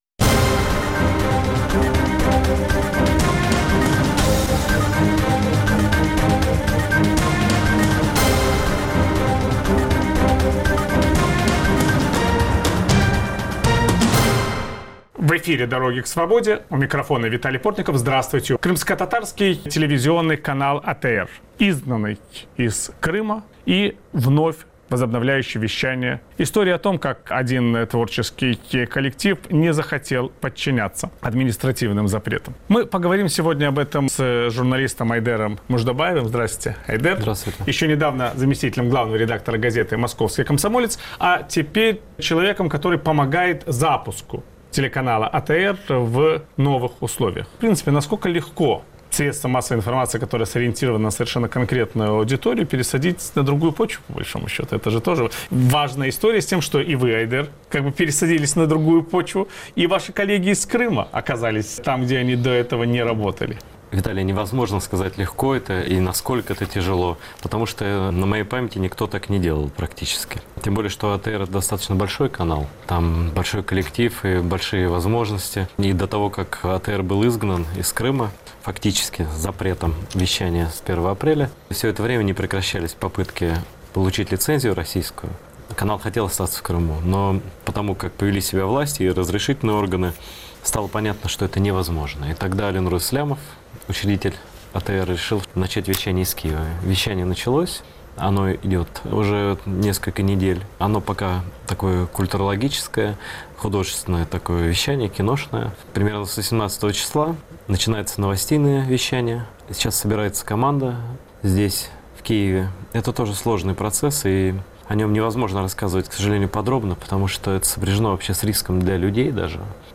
Крымскотатарский канал ATR, изгнанный из Крыма, возвращается к своей аудитории, начав вещание из Киева. О будущем телеканала ведущий программы "Дороги к свободе" Виталий Портников беседует с известным российским журналистом Айдером Муждабаевым.